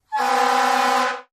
fo_fireengine_horn_02_hpx
Fire engines sounds horn. Siren, Fire Engine Horn Horn, Fire Engine